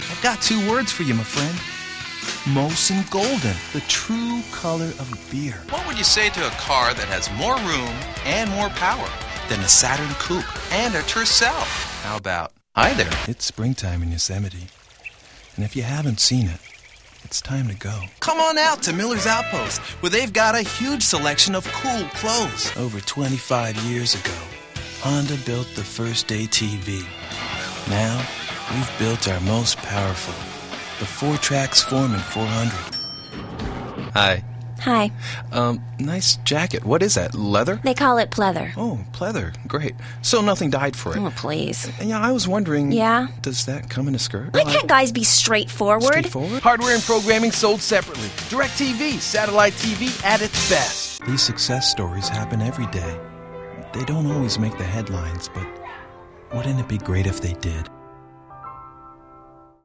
Voix off
- Baryton Ténor